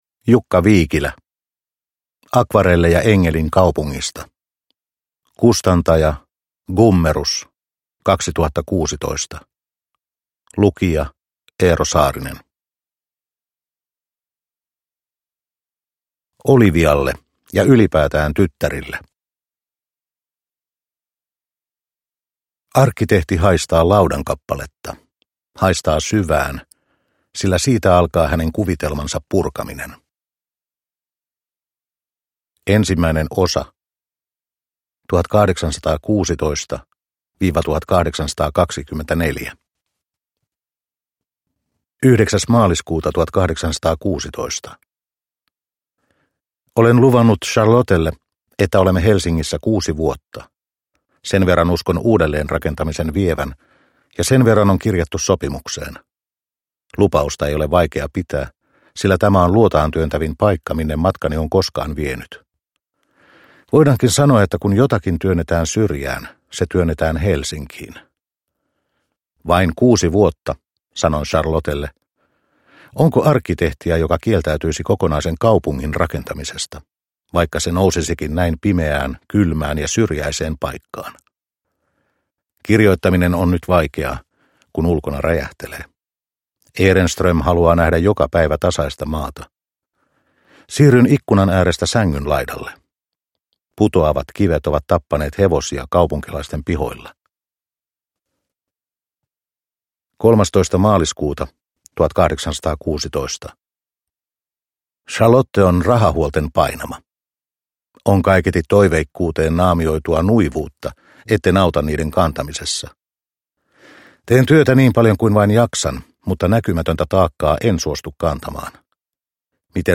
Akvarelleja Engelin kaupungista – Ljudbok